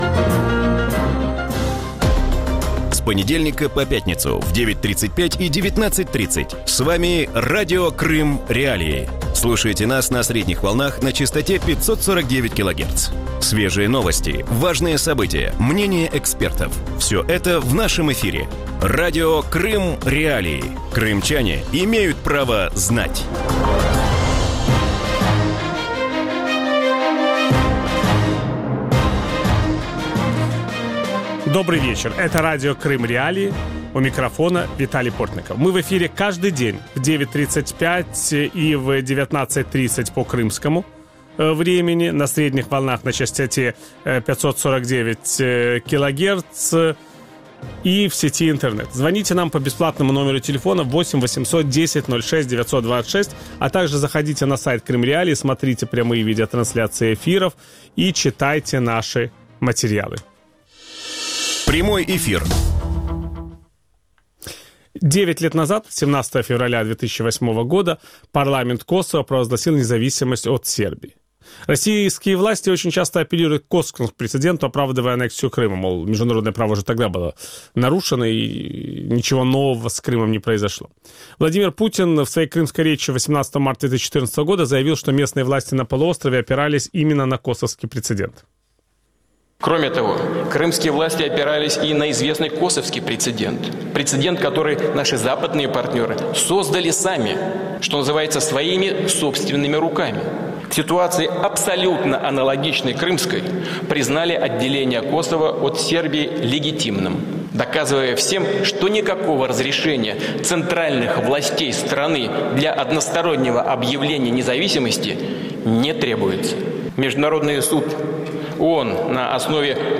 В вечернем эфире Радио Крым.Реалии сравнивают аннексию Крыма Россией с признанием независимости Косово Западом. Почему российские власти апеллируют к косовскому прецеденту, оправдывая захват украинского полуострова, и в чем различие между двумя кейсами?